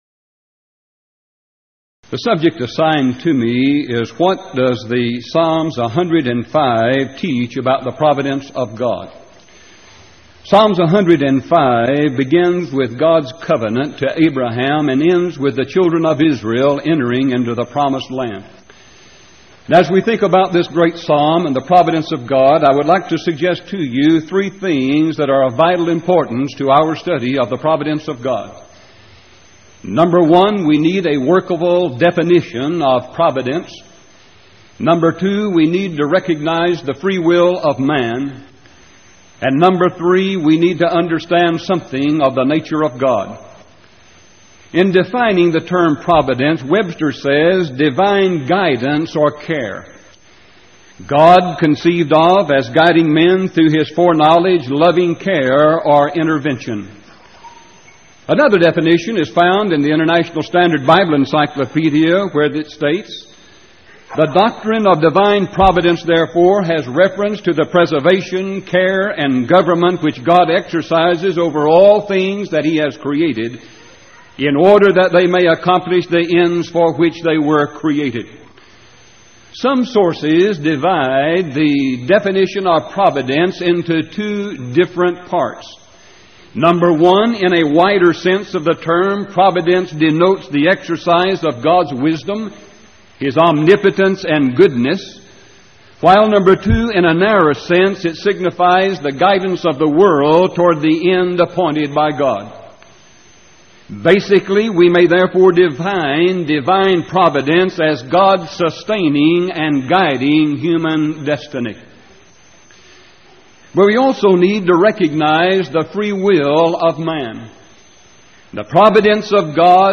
Event: 1989 Power Lectures
If you would like to order audio or video copies of this lecture, please contact our office and reference asset: 1989Power33